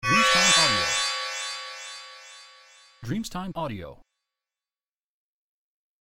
Carillon magico della colata di incantesimo di aspetto
• SFX